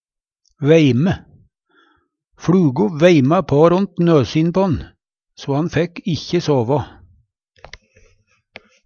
veime - Numedalsmål (en-US)
DIALEKTORD PÅ NORMERT NORSK veime vimse, vera i stadig rørsle Infinitiv Presens Preteritum Perfektum Eksempel på bruk FLugo veima på ront nøsin på'n, so han fekk ikkje såva.